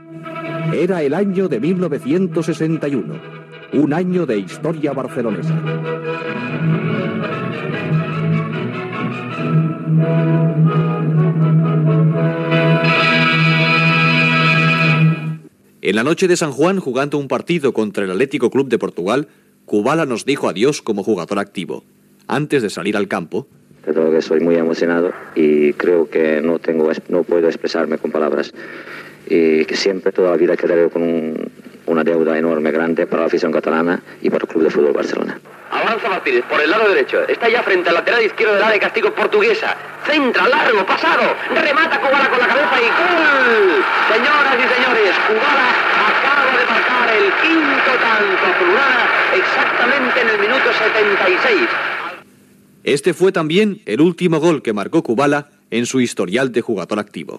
La retirada com a jugador del Futbol Club Barcelona de Ladislao Kubala. Amb una recreació de la narració del seu últim gol
Informatiu